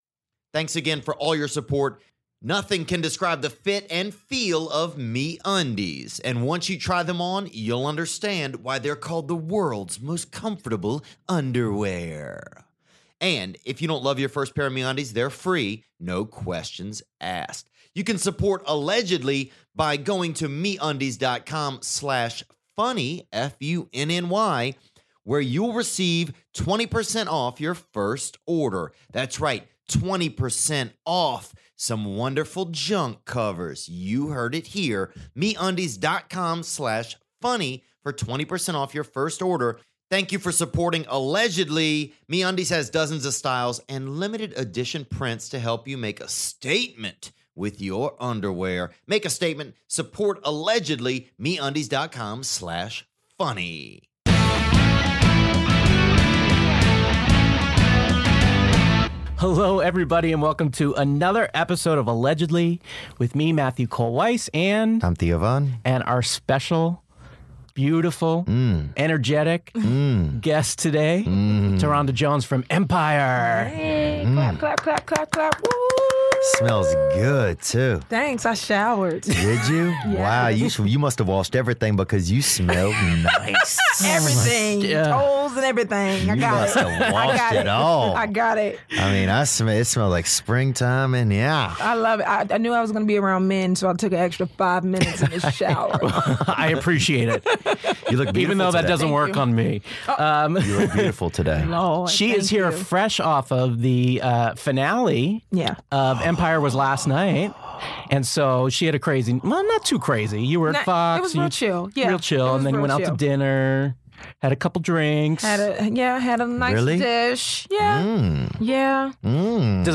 Well, Porsha herself, Ta'Rhonda Jones, is in the studio to talk all about that climactic pregnant woman falling to her possible death finale and what it's really like to be on a huge hit like this. Jones also opens up a lot about her past - including her brother's death, her time working at a nursing home (and the old dudes who groped her), and her failed engagement. But none of that compares to the kick she gets out of playing judge between the hosts which ends in an epic freestyle rap battle for the ages.